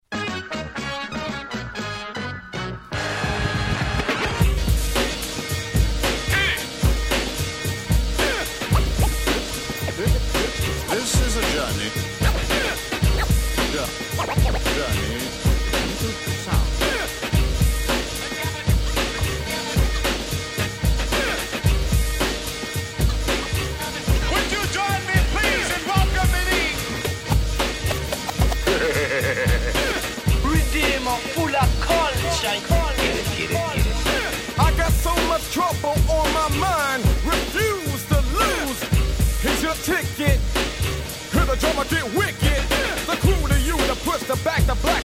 89' Smash Hit Hip Hop !!
イントロからブチ上がりのダンサー受け抜群の1曲！！